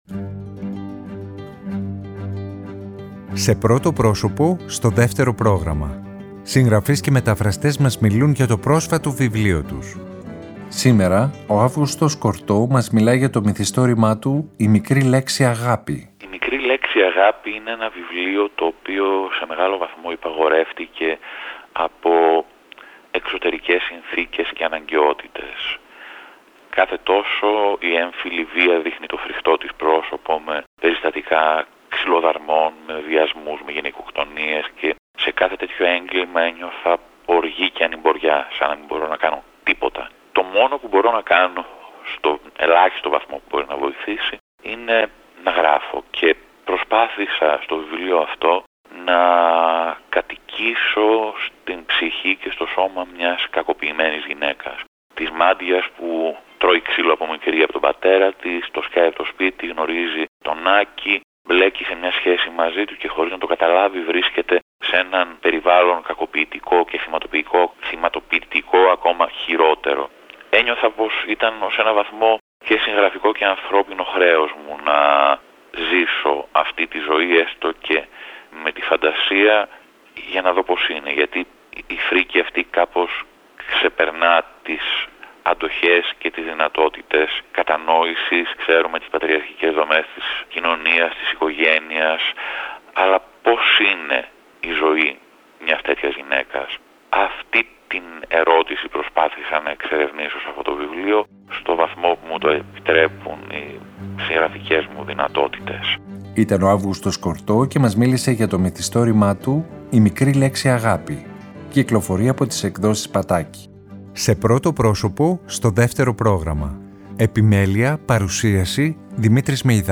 Σήμερα ο Αύγουστος Κορτώ μας μιλάει για το μυθιστόρημα του «Η μικρή λέξη αγάπη».